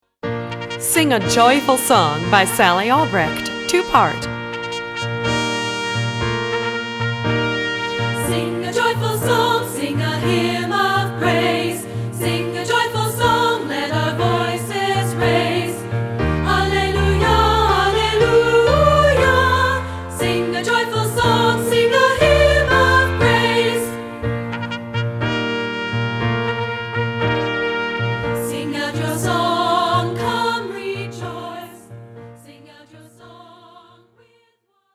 Voicing: 2-Part